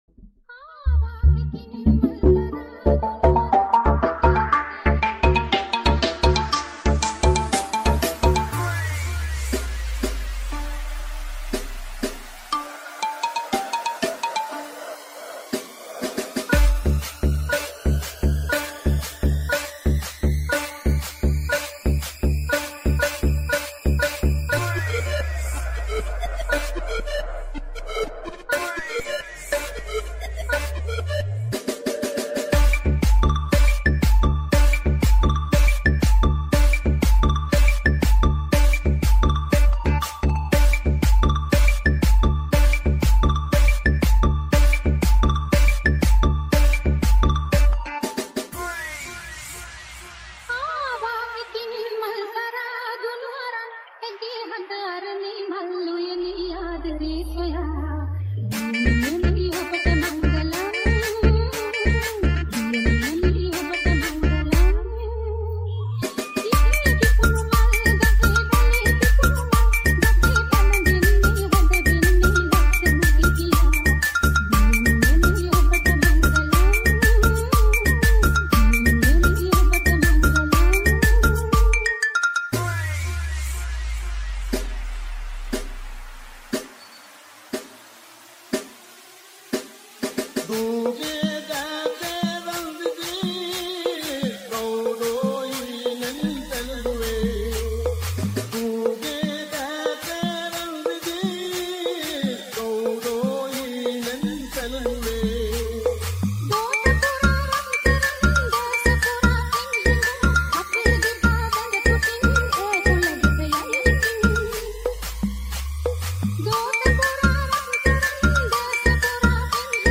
EDM Remix New Song